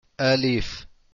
001-alif-audio-aussprache-arabisch.mp3